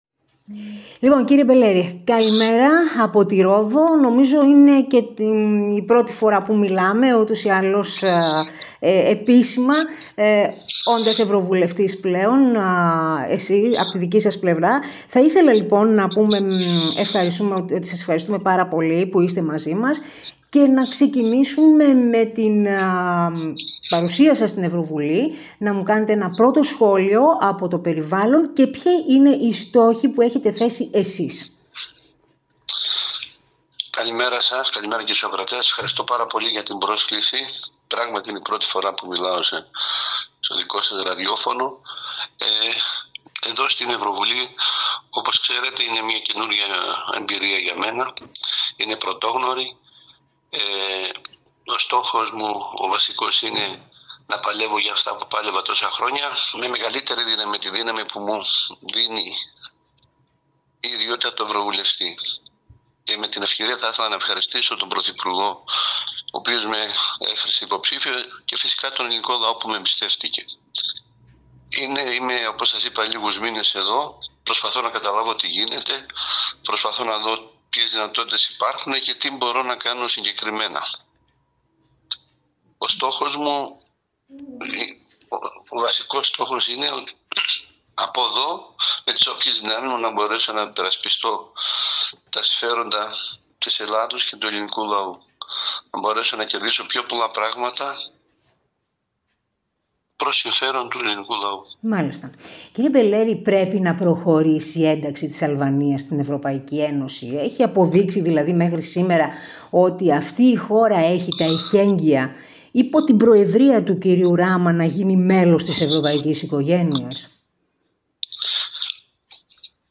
αποκλειστική συνέντευξη